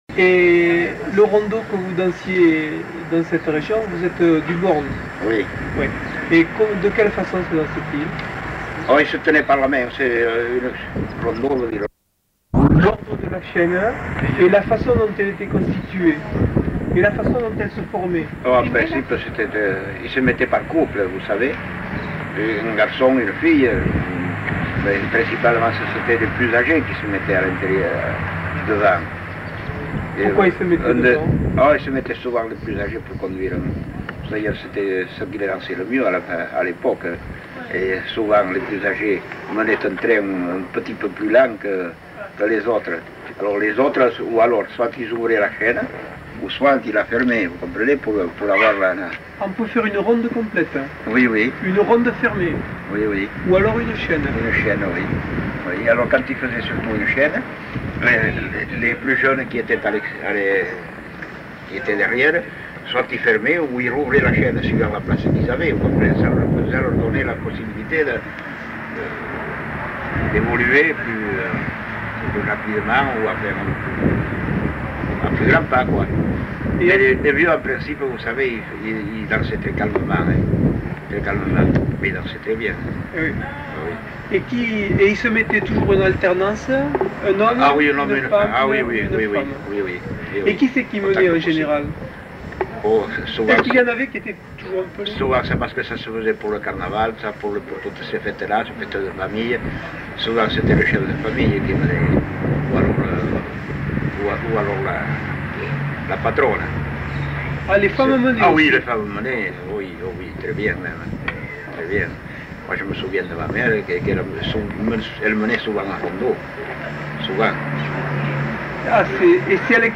Lieu : Mimizan
Genre : témoignage thématique